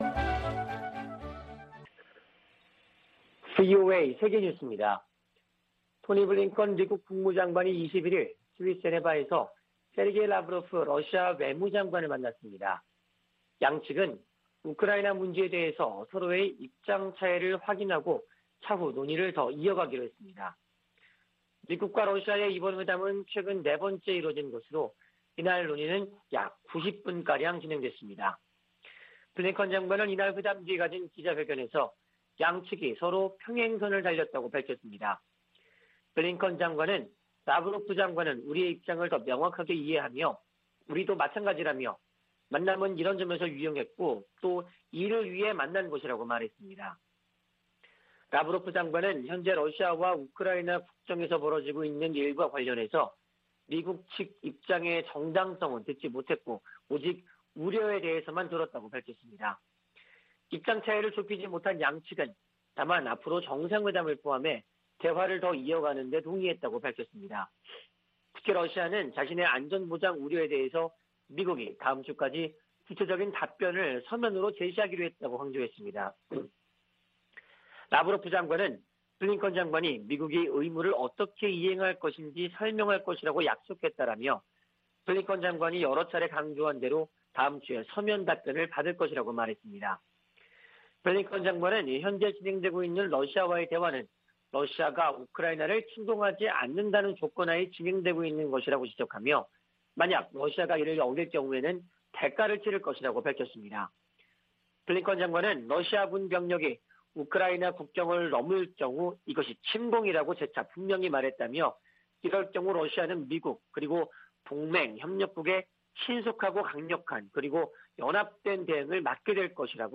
VOA 한국어 아침 뉴스 프로그램 '워싱턴 뉴스 광장' 2021년 1월 22일 방송입니다. 미국 등 8개국이 북한의 탄도미사일 발사를 전 세계에 대한 위협으로 규정하고 유엔에서 추가 제재를 촉구했습니다. 백악관은 북한이 무기 시험 유예를 해제할 수 있다는 뜻을 밝힌 데 대해, 대량살상무기 개발을 막을 것이라고 강조했습니다. 북한이 선대 지도자들의 생일을 앞두고 열병식을 준비하는 동향이 포착됐습니다.